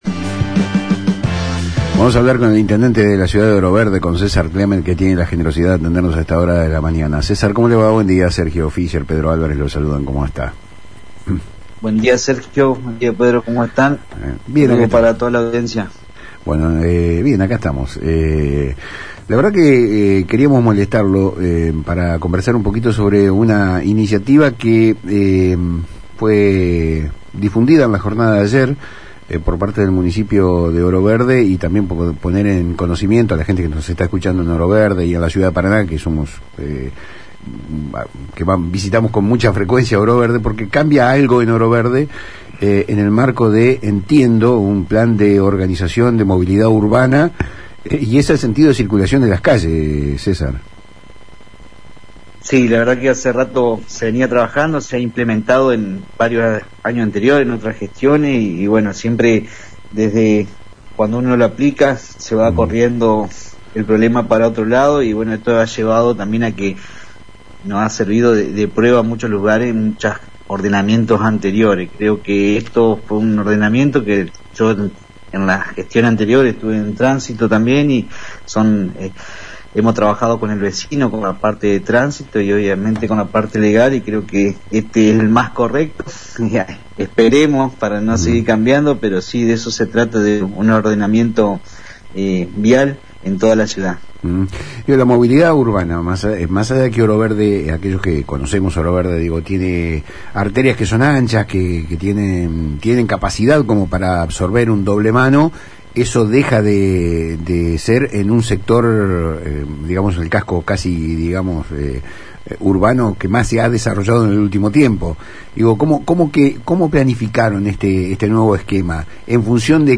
En una entrevista en Palabras Cruzadas por FM Litoral el intendente César Clement, se revelaron detalles sobre un plan de reordenamiento del tránsito, la continuidad de la obra pública con fondos propios y una práctica administrativa que resalta por su atípica transparencia: la licitación del combustible municipal.